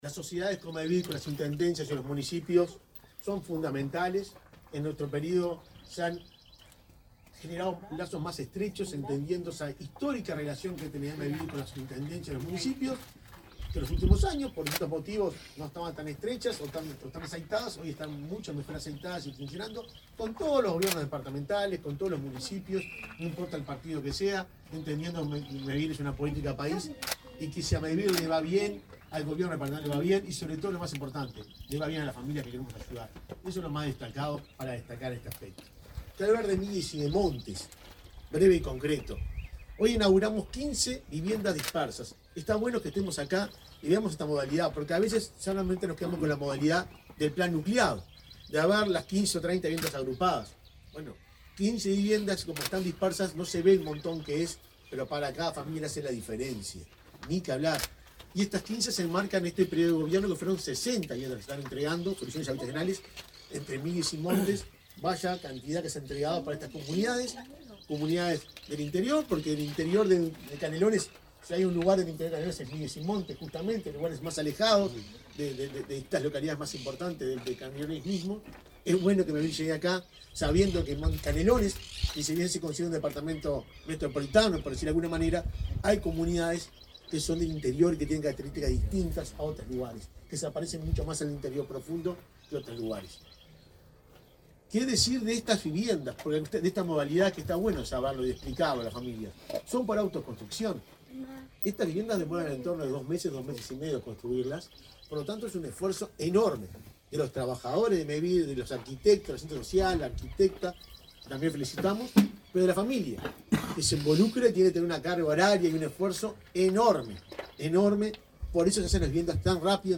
Palabras del presidente de Mevir, Juan Pablo Delgado
Palabras del presidente de Mevir, Juan Pablo Delgado 30/11/2023 Compartir Facebook X Copiar enlace WhatsApp LinkedIn Este jueves 30 en Canelones, el presidente de Mevir, Juan Pablo Delgado, participó de la inauguración de 15 viviendas dispersas en la localidad de Montes.